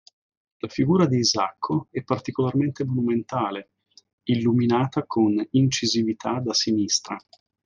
Read more Noun Verb Adj Opposite of destra, dritta Frequency B1 Hyphenated as si‧nì‧stra Pronounced as (IPA) /siˈnis.tra/ Etymology Inherited from Latin sinister.